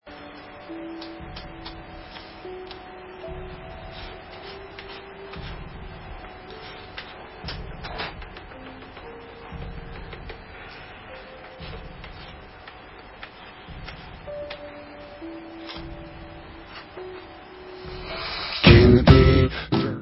Allstar dutch rockband